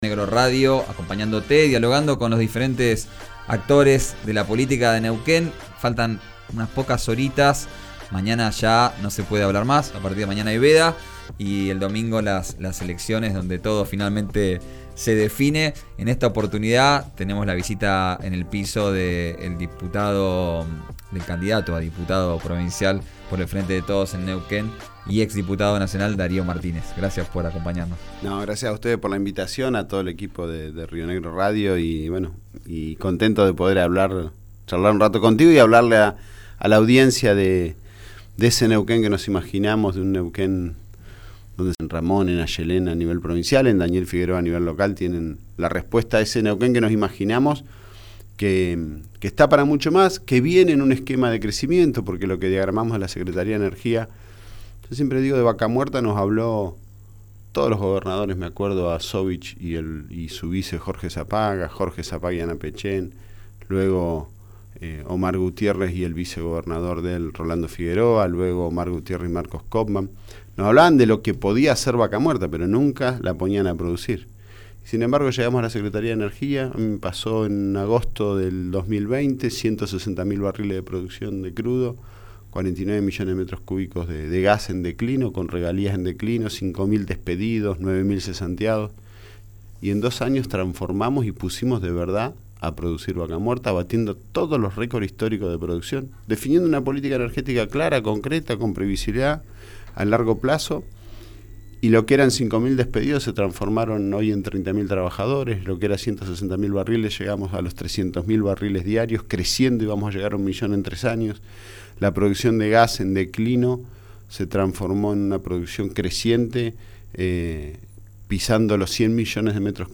El exsecretario de Energía de la Nación y candidato a diputado por el Frente de Todos Neuquino visitó el estudio de RÍO NEGRO RADIO. Escuchá la entrevista completa.